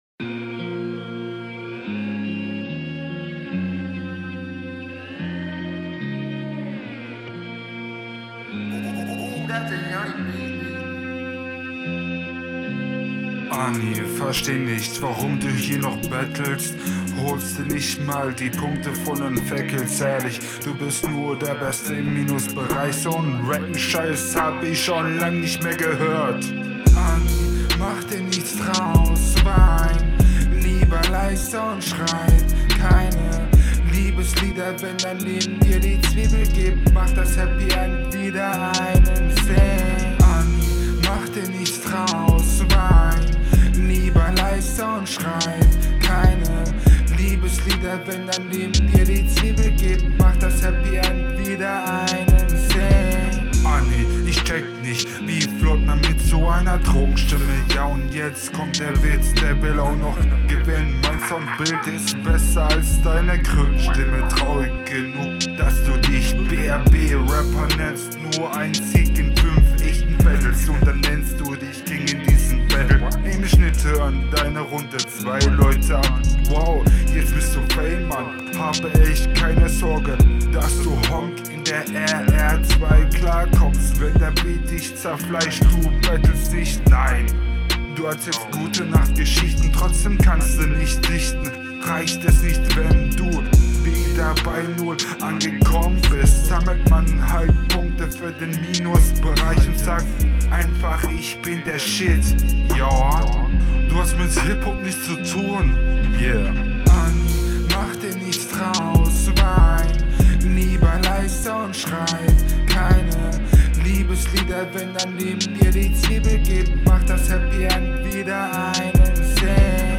Die Hook ist furchtbar .. Lass autotune bitte und wenn dann verwende es mit bedacht …